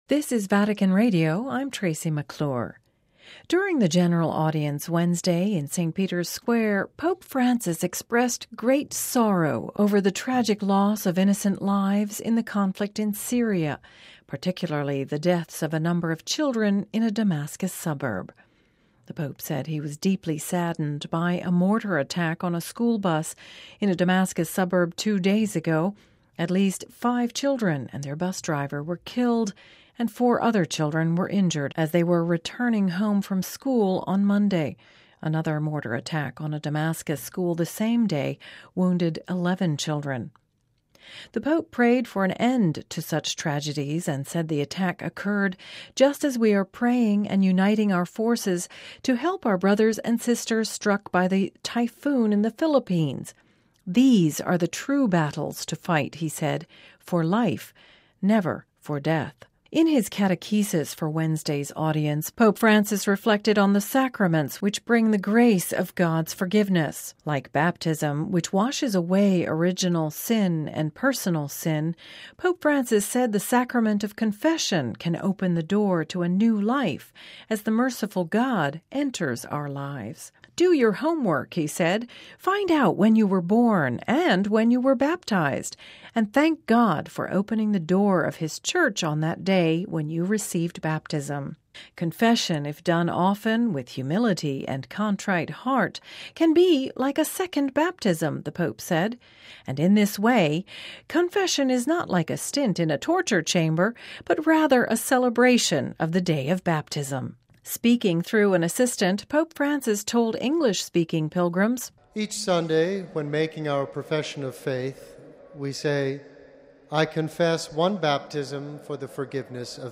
(Vatican Radio) During the General Audience Wednesday in St. Peter’s square, Pope Francis expressed great sadness over the tragic loss of innocent lives in the conflict in Syria, particularly the deaths this week of a number of school children in Damascus.